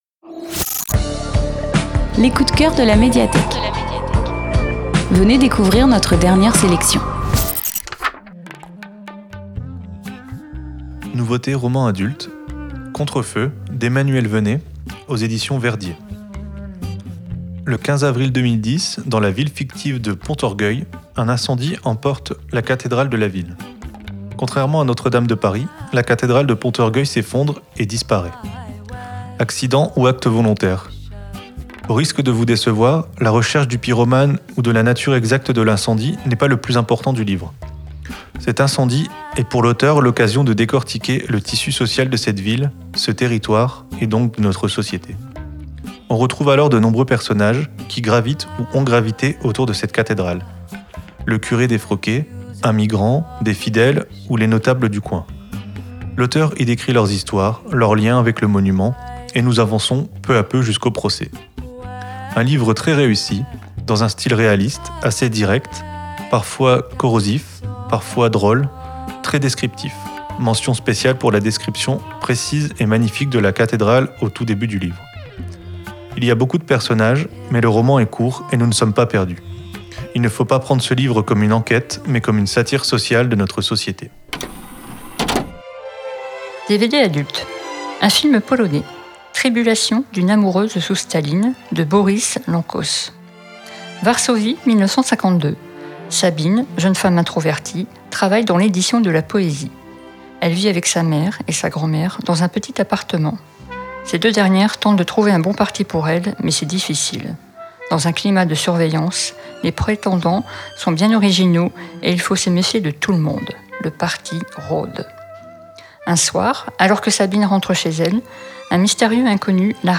Tous les mardis à 15h00, l’équipe de la Médiathèque de Villebon vous présente ses quelques coups de cœurs : livres, DVD, événements…